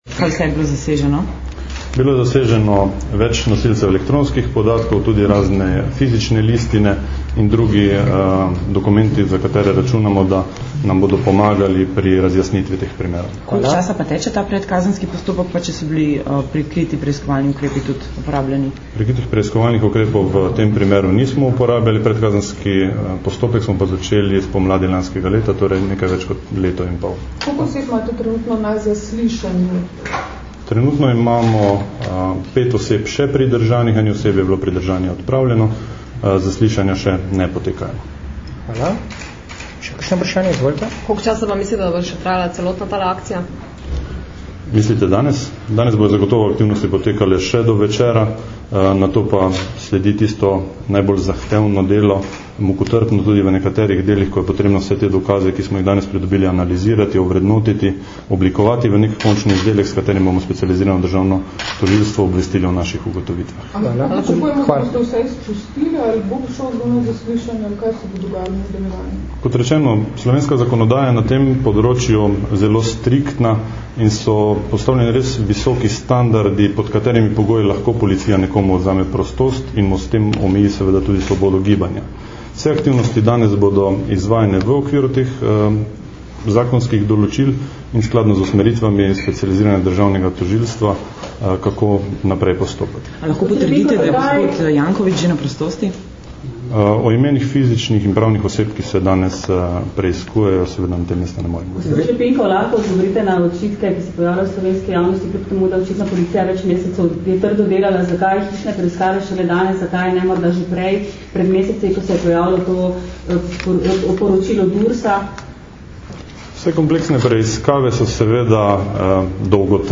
Novinarska vprašanja in odgovori nanje (mp3)